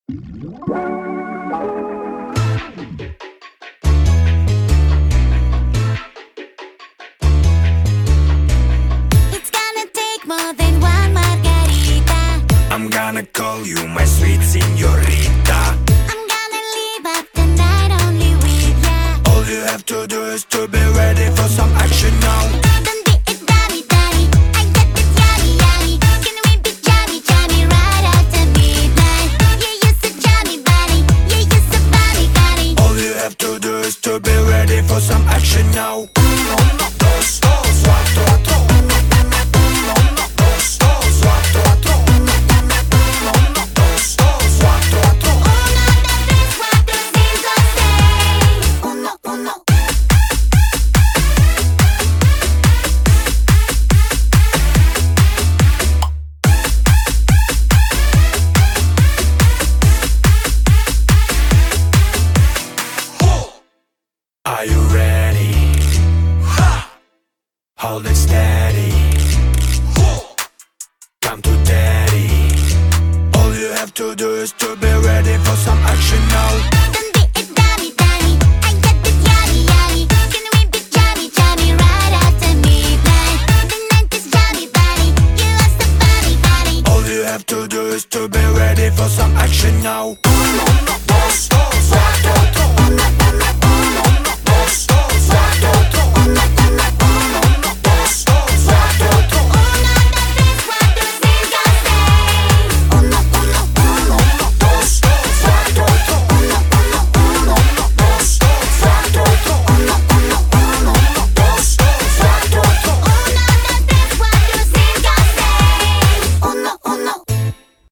BPM142
Audio QualityMusic Cut